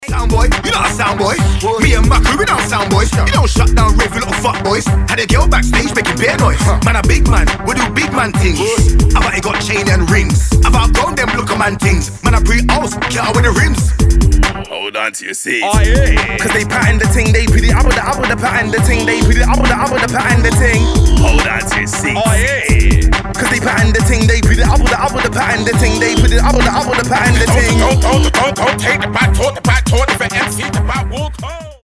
• Качество: 320, Stereo
мужской вокал
Хип-хоп
качающие
быстрые
Bass
Нарезка кайфового трека, созданного 10 исполнителями!